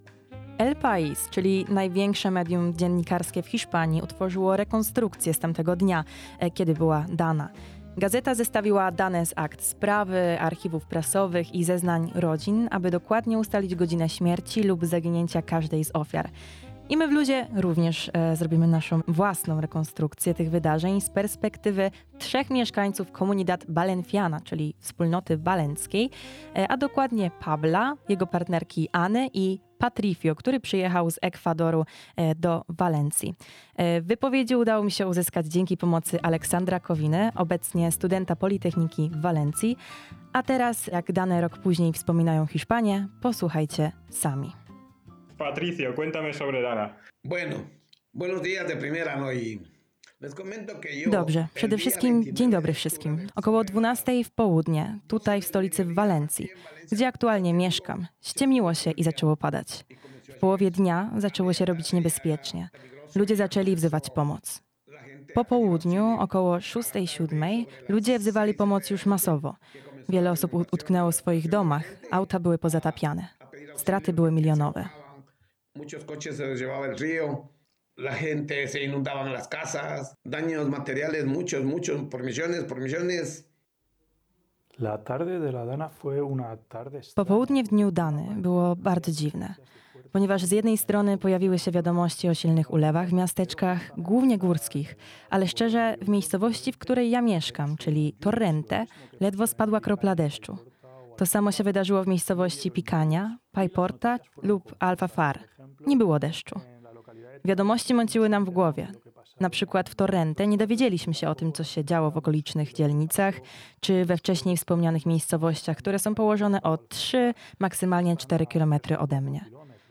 Podczas audycji El ritmo que nos une mogliśmy wysłuchać relacji samych Hiszpanów:
relacje-Hiszpanow-El-ritmo-que-nos-une.mp3